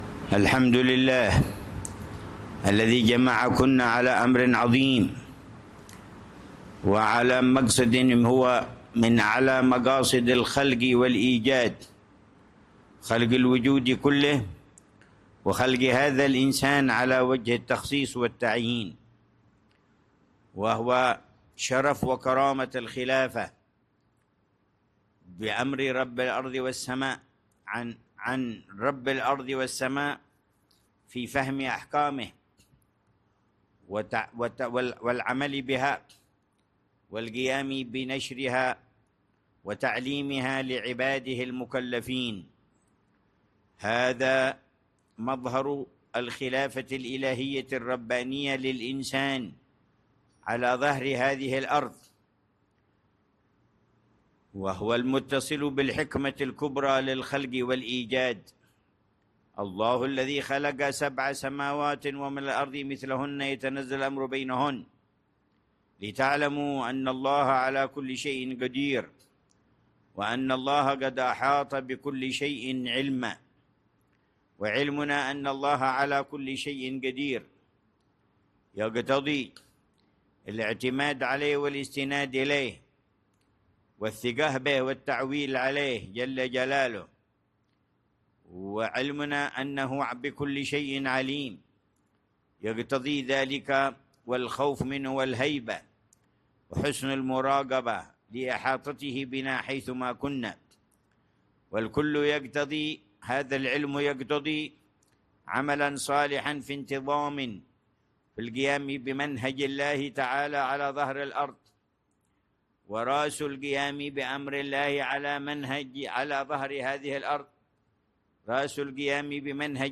محاضرة للداعيات ضمن فعاليات ملتقى الدعاة التاسع عشر
محاضرة العلامة الحبيب عمر بن حفيظ، للداعيات، ضمن فعاليات ملتقى الدعاة (التاسع عشر)